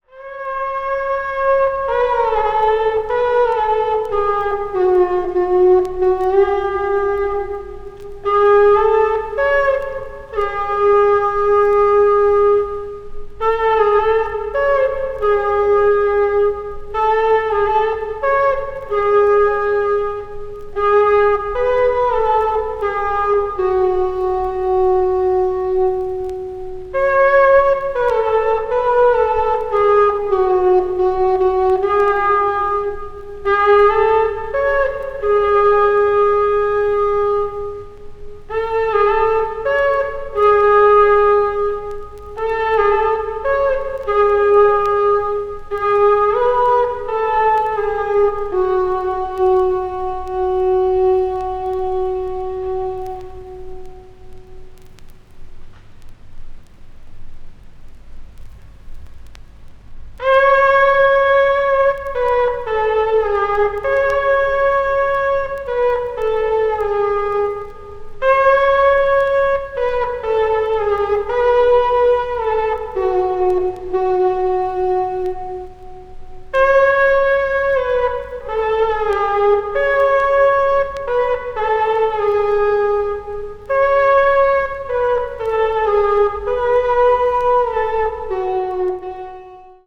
Ancient Swedish Pastoral Music